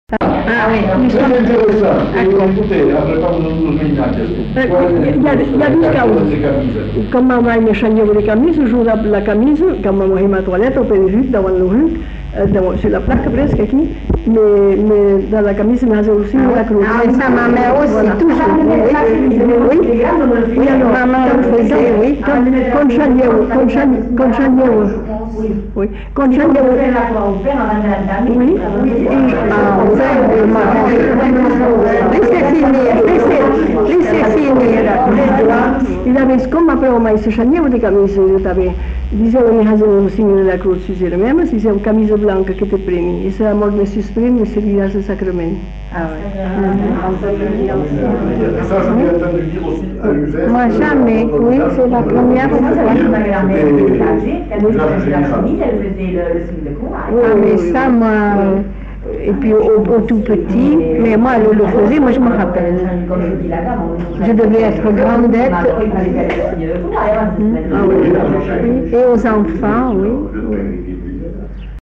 Lieu : Bazas
Genre : conte-légende-récit
Effectif : 1
Type de voix : voix de femme
Production du son : récité
Classification : prière